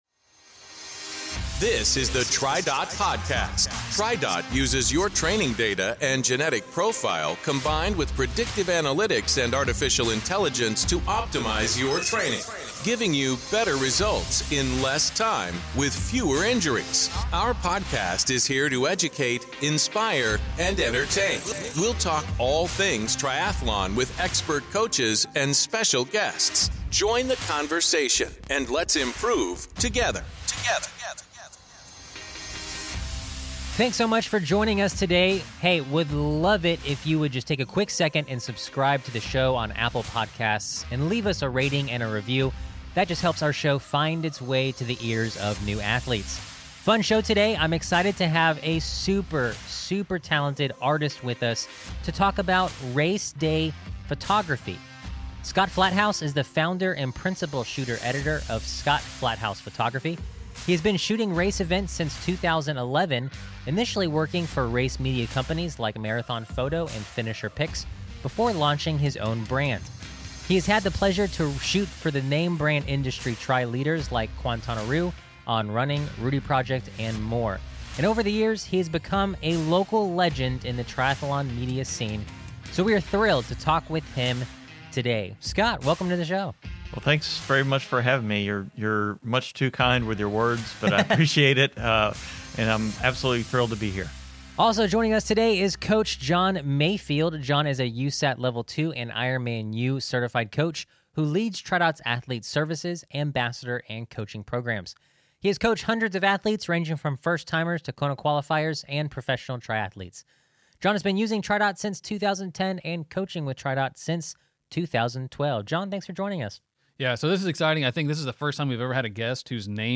Capturing the Moment: An Interview with a Race-Day Photographer